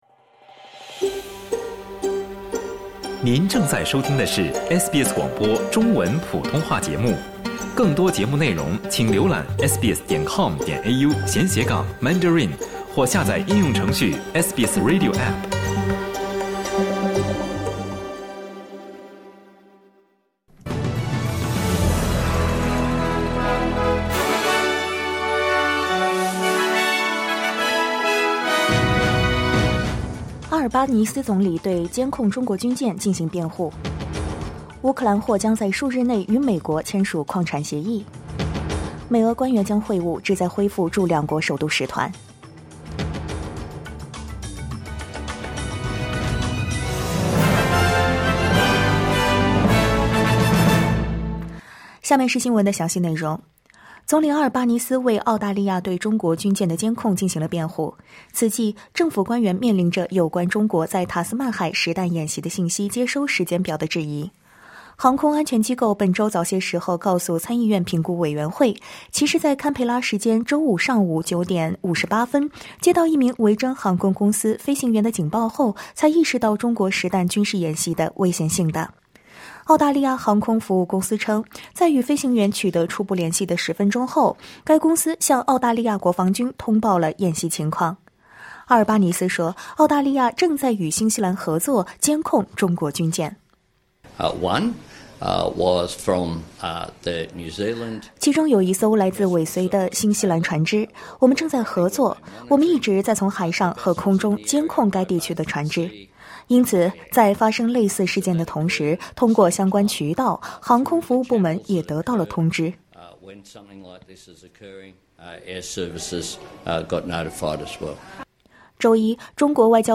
SBS早新闻（2025年2月27日）